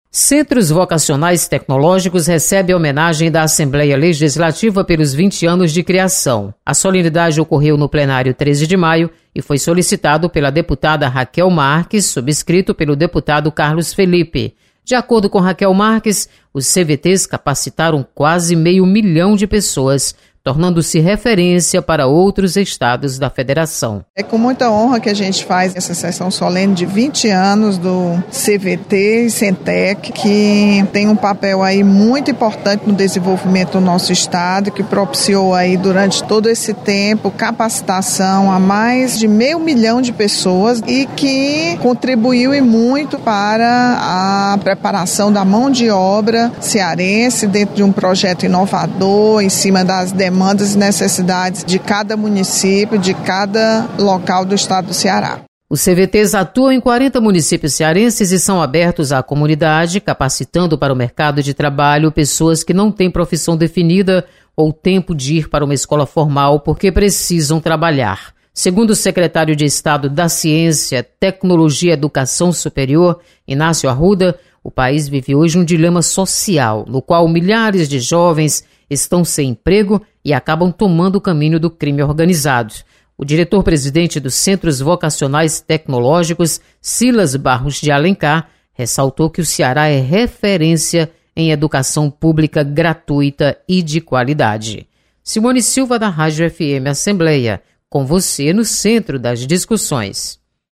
Você está aqui: Início Comunicação Rádio FM Assembleia Notícias Solenidade